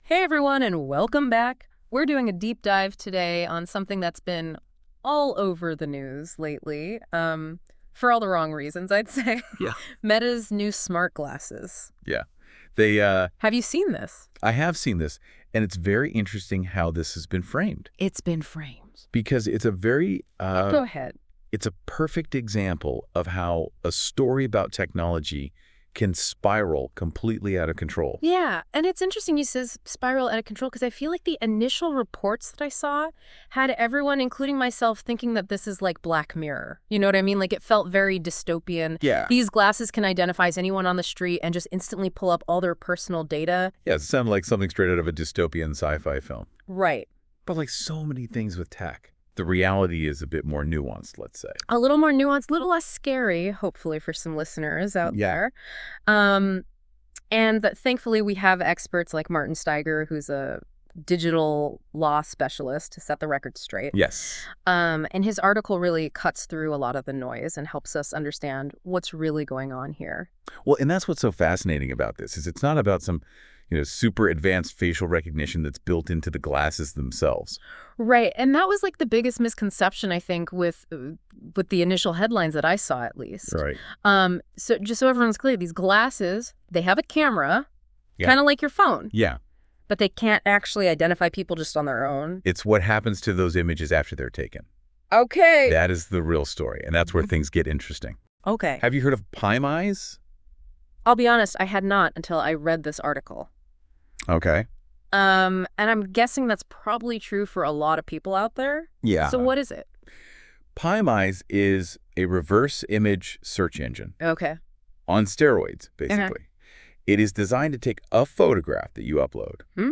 Nachtrag: NotebookLM, der neue KI-Dienst von Google, hat eine sehr amerikanische, aber durchaus hörbare Podcast-Episode über diesen Beitrag erstellt.
i-xray-meta-glasses-notebooklm-podcast-episode-001.wav